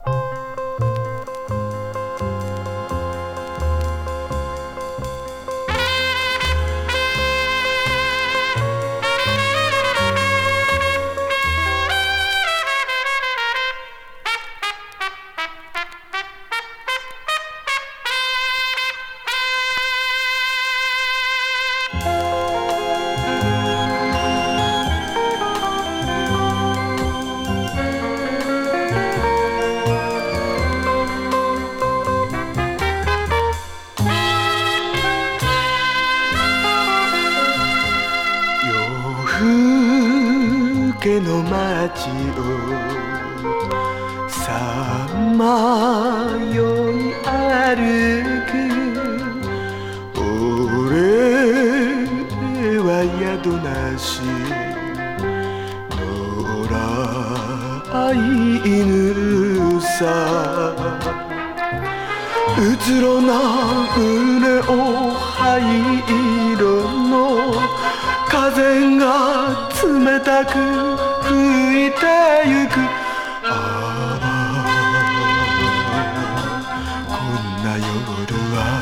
魅惑のハスキーボイス！絞り出すように歌うスタイルがシビれます！マイナー歌謡。